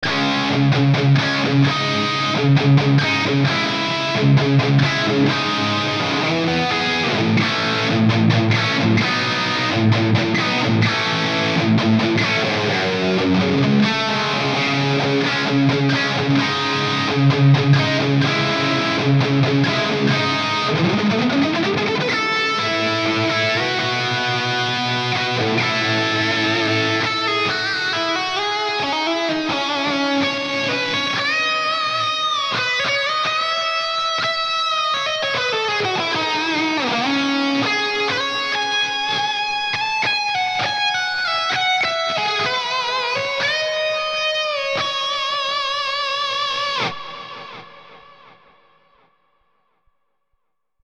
This Amp Clone rig pack is made from a Marshall JVM 410H Preamp.
IR USED: MARSHALL 1960A V30 SM57+ E906 POS 1
RAW AUDIO CLIPS ONLY, NO POST-PROCESSING EFFECTS
Hi-Gain